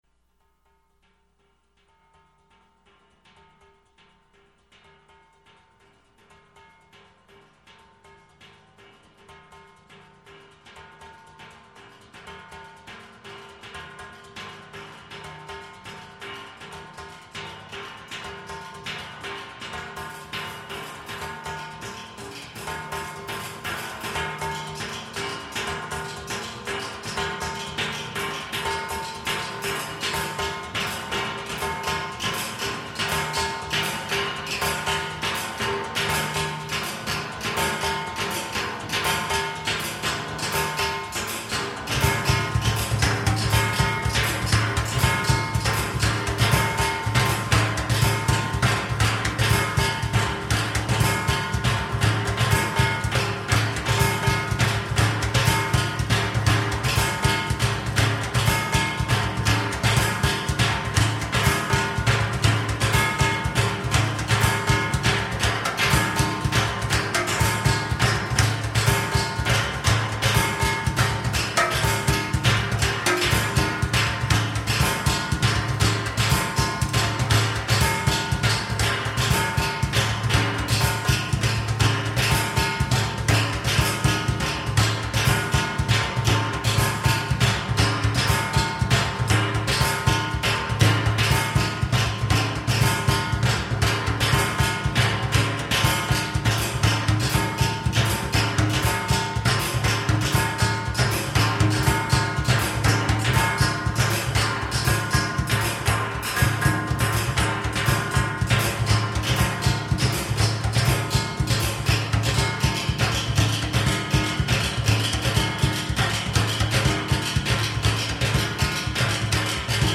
experimental percussion & noise duo   web page
Army gas can, guitar
Recorded at Experimental Sound Studio, Chicago, fall 1990.
Percussion and feedback.